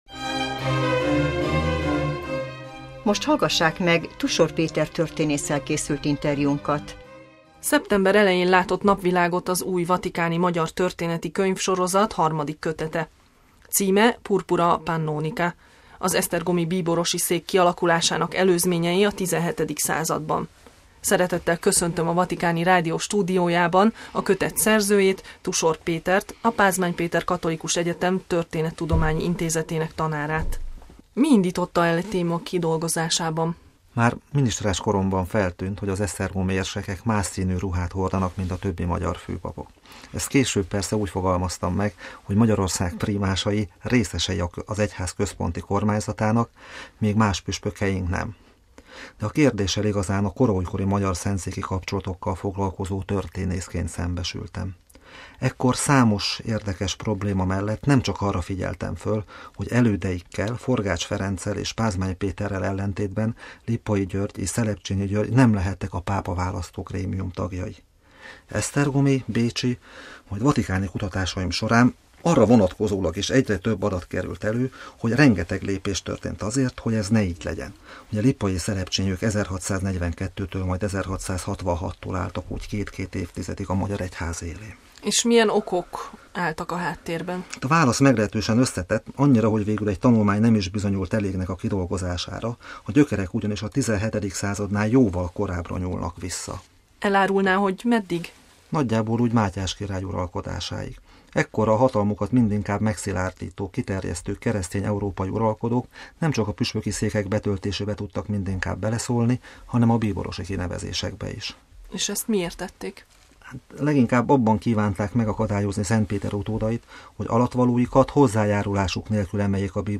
Megjelent a vatikáni-magyar történeti sorozat 3. kötete - Interjú